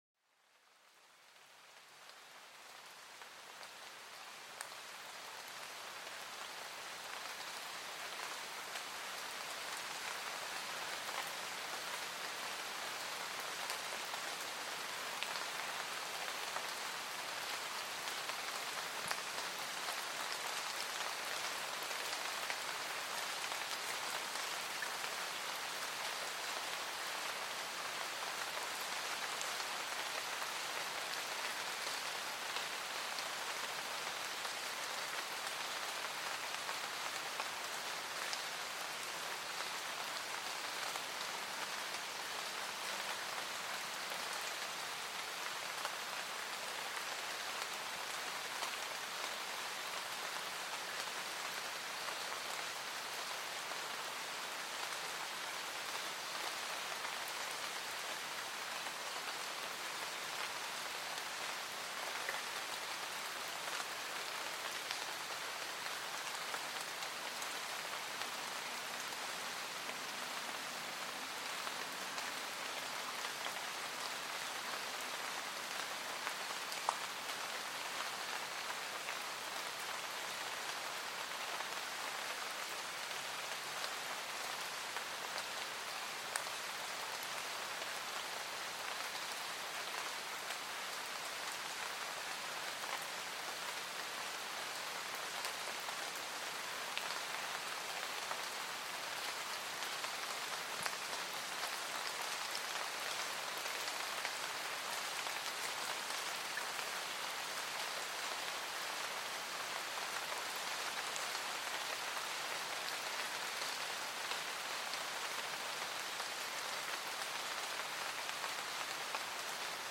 Descubre el sonido armónico de la lluvia cayendo suavemente en el bosque, un verdadero concierto natural relajante. Déjate transportar por esta melodía húmeda que acuna tus sentidos y borra el estrés del día.
SONIDOS DE LA NATURALEZA PARA LA RELAJACIÓN